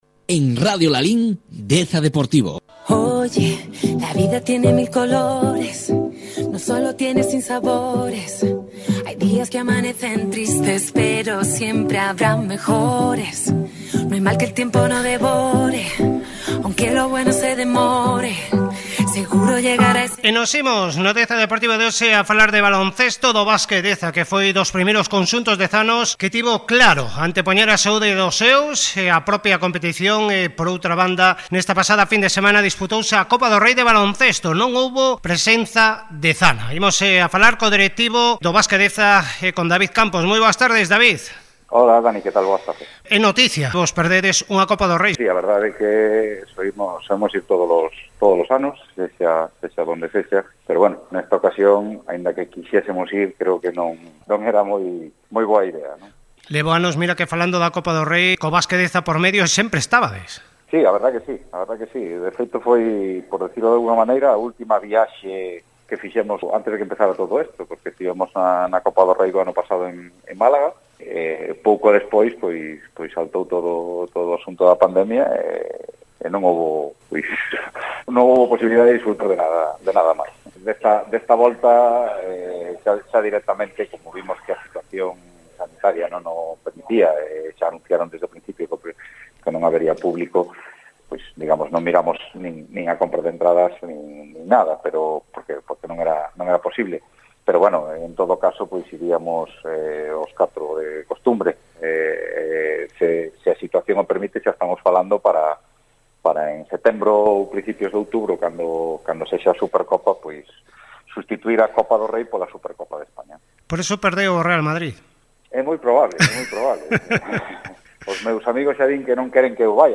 Entrevistas Radio Lalín – Basketdeza